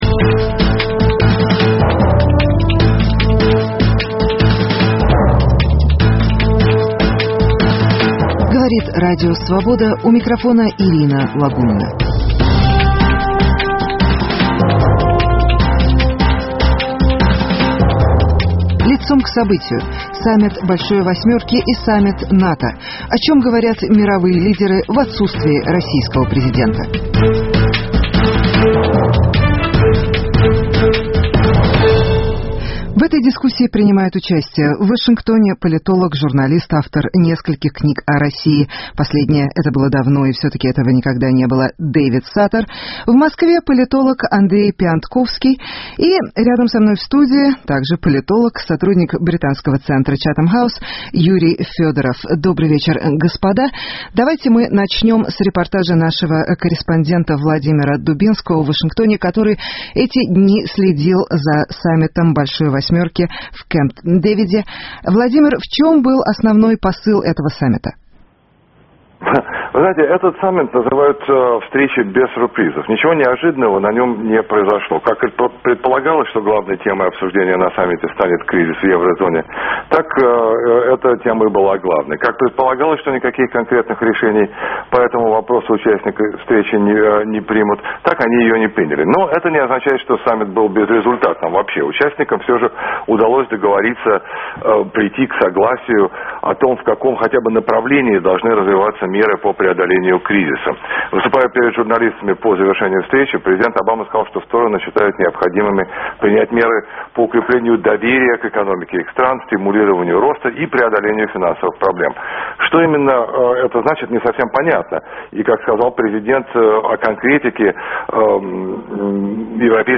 О чем будут говорить мировые лидеры в отсутствии российского президента. Прямой эфира Радио Свобода.